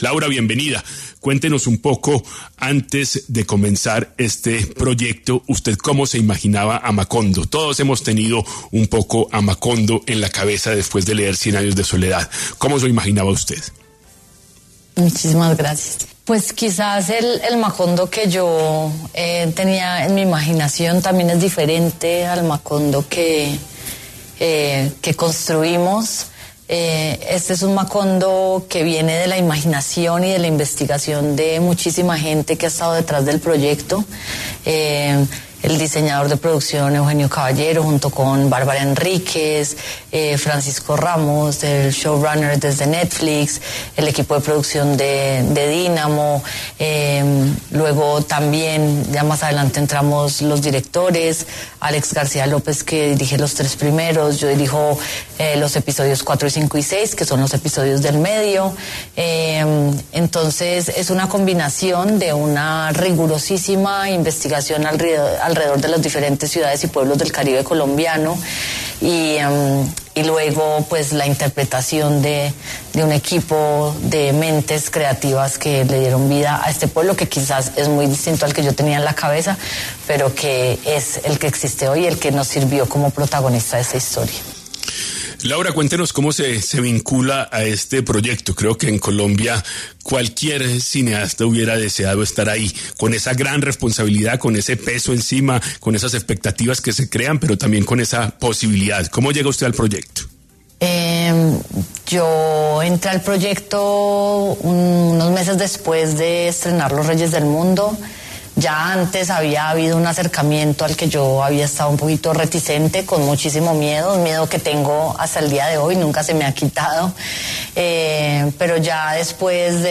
En #10AM estuvo Laura Mora, directora de cine y guionista colombiana, quien habló sobre el estreno de la serie “Cien años de soledad” que se hizo hoy en Netflix.